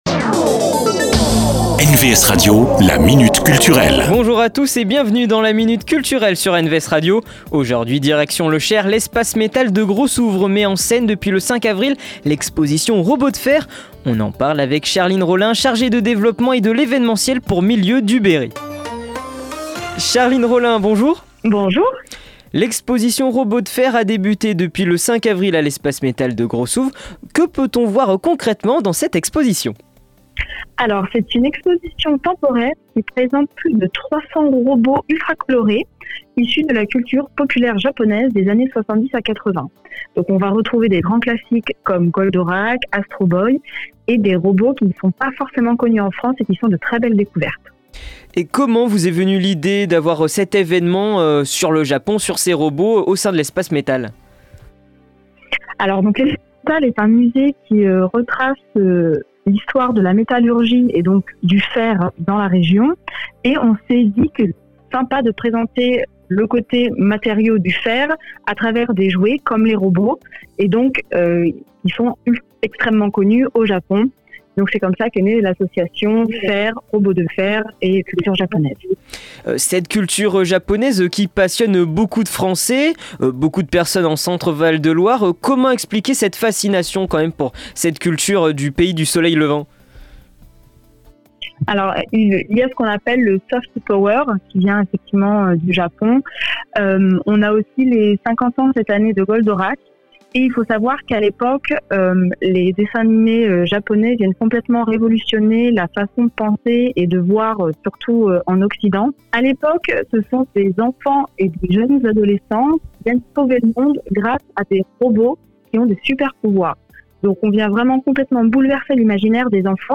La Minute Culture, rencontre avec les acteurs culturels de votre territoire.